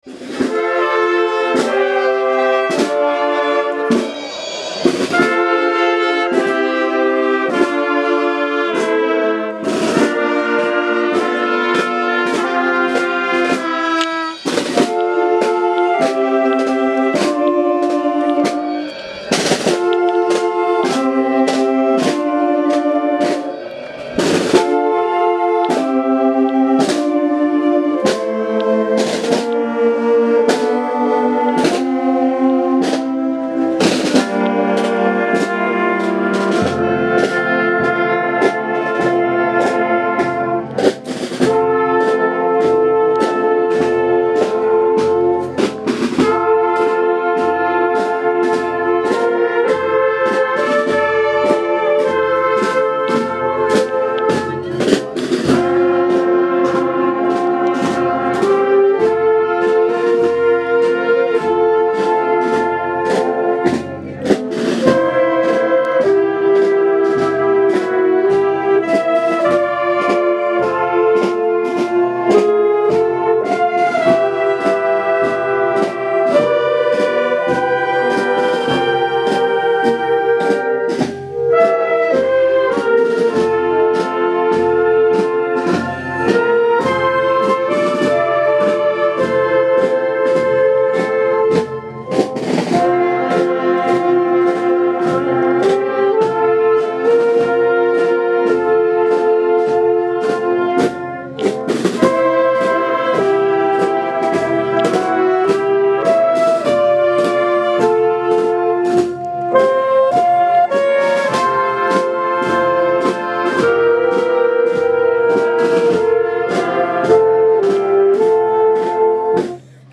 El pasado sábado 5 de septiembre tuvo lugar la procesión en honor la Virgen de Las Huertas, acompañada por vecinos, autoridades municipales y religiosas, y la Banda de Música de la Agrupación Musical de Totana.